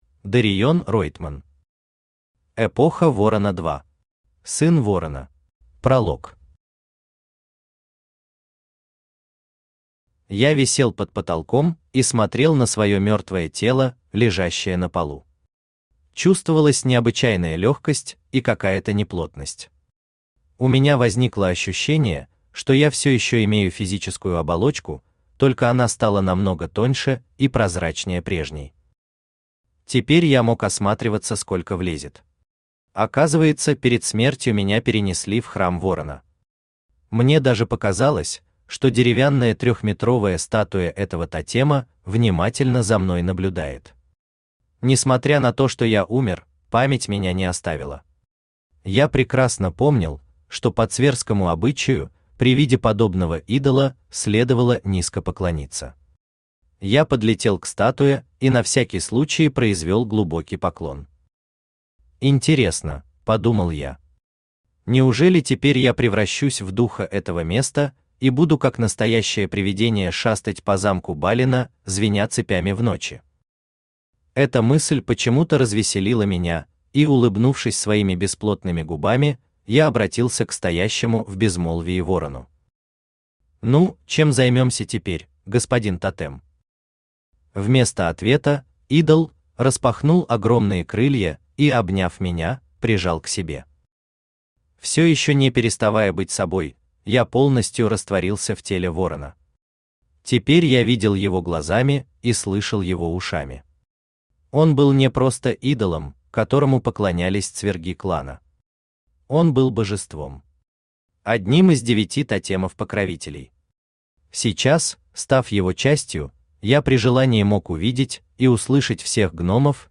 Аудиокнига Эпоха Ворона 2. Сын Ворона | Библиотека аудиокниг
Сын Ворона Автор Дариен Ройтман Читает аудиокнигу Авточтец ЛитРес.